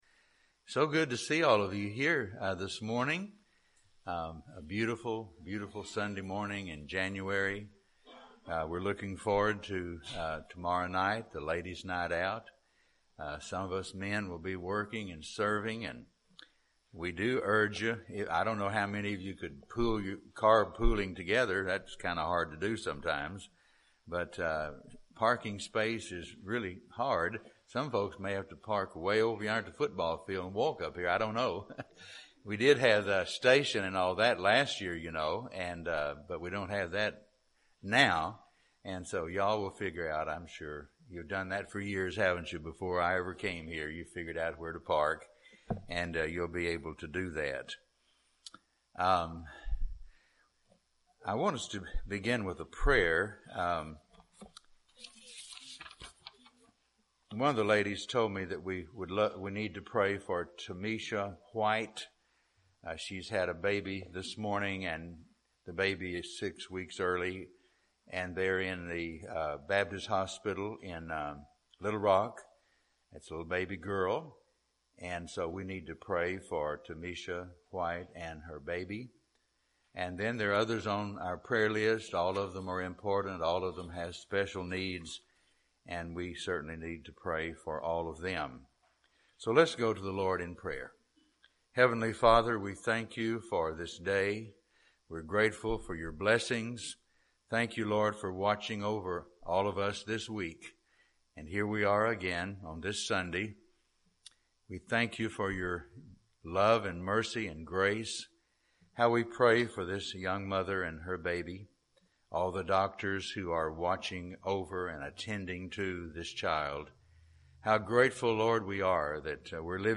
Morning message from Mark 15:40-41.